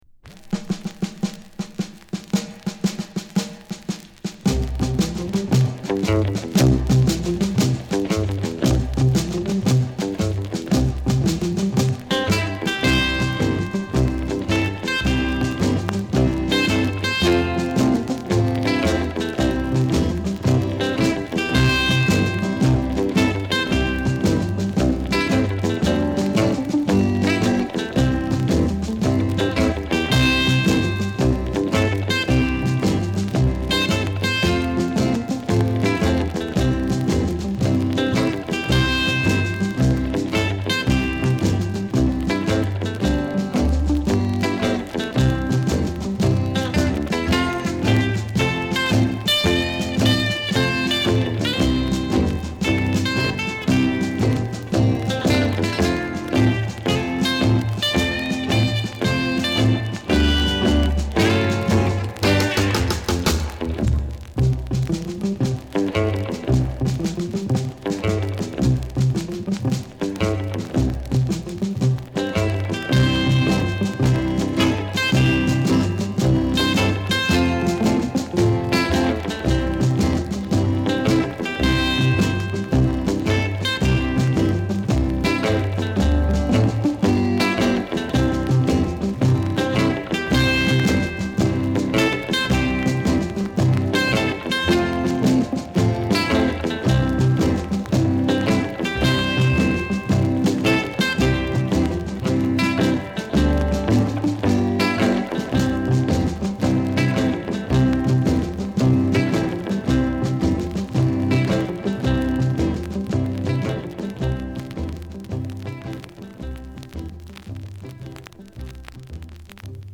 盤面はきれいですが、プレスの状態が悪いのかバックグラウンドノイズはいります。